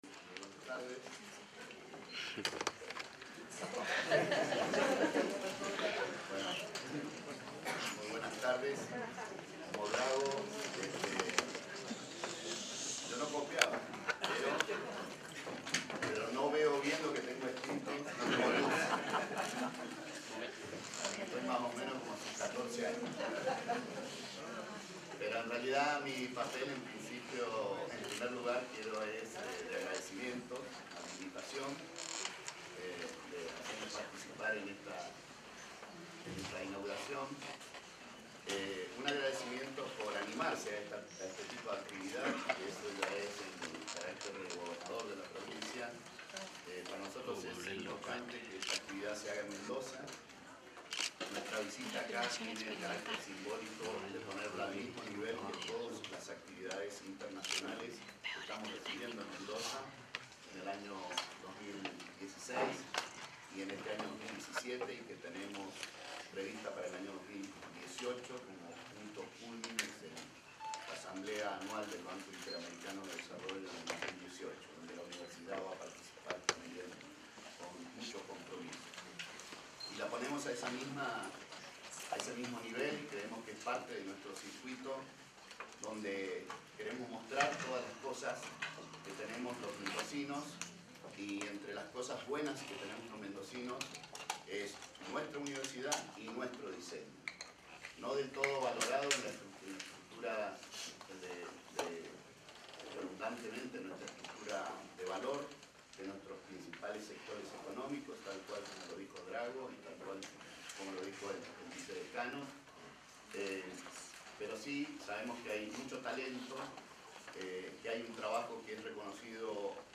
El Gobernador participó de la apertura del IV Congreso Latinoamericano DiSUR.
25-GOBERNACION-Congreso-de-Diseño-DISCURSO-CORNEJO.mp3